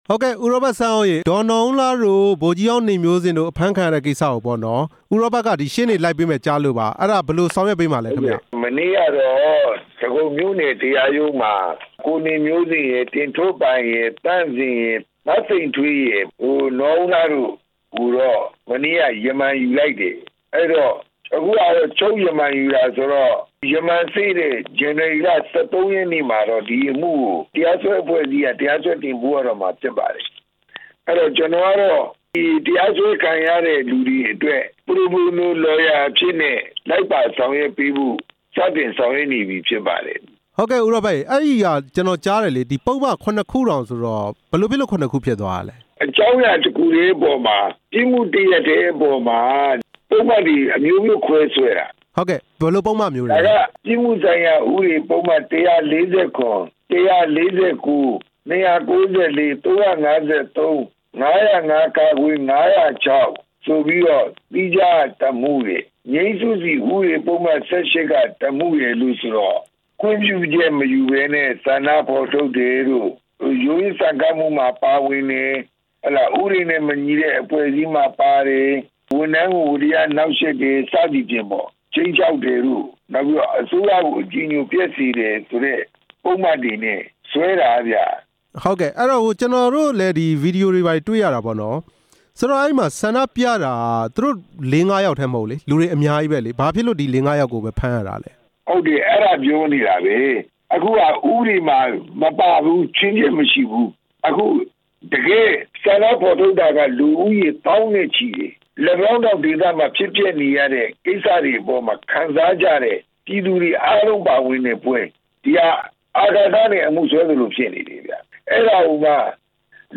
တက်ကြွလှုပ်ရှားသူတွေကို ဖမ်းဆီးမှု မေးမြန်းချက်